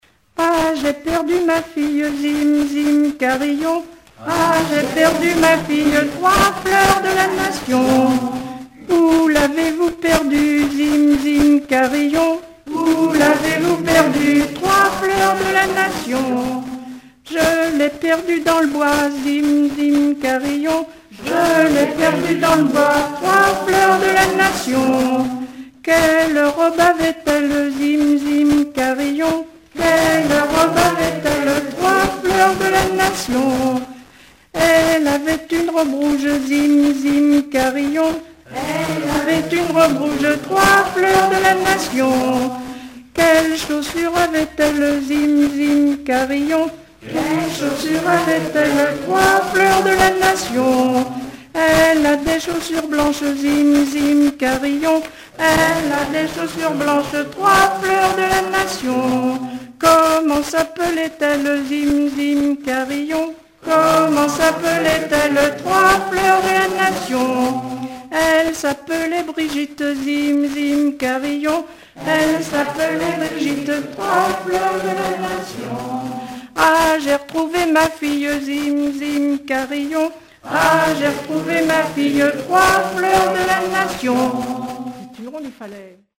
Mervent ( Plus d'informations sur Wikipedia ) Vendée
rondes enfantines (autres)